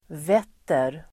Ladda ner uttalet
vetta verb, face Grammatikkommentar: x & åt y Uttal: [v'et:er] Böjningar: vette, vettat, vetta, vetter Definition: vara vänd åt visst håll Exempel: sovrumsfönstren vetter åt väster (the bedroom windows face west)